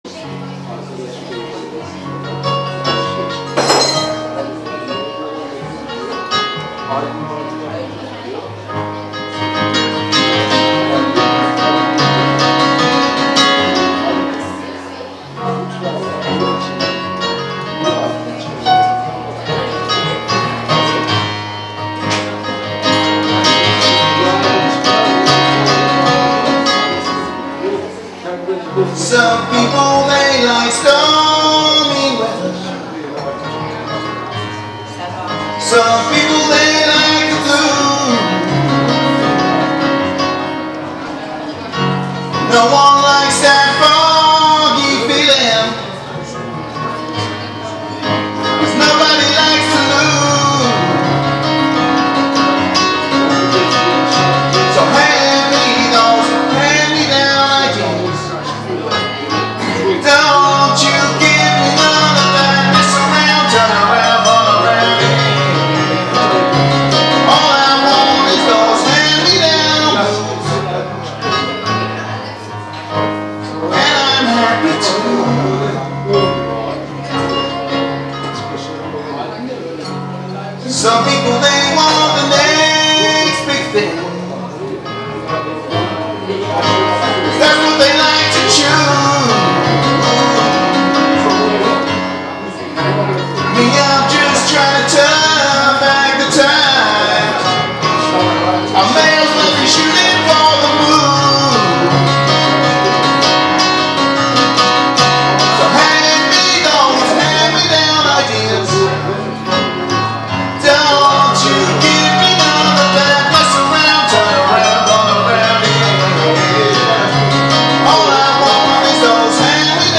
Solo  Piano/Vocals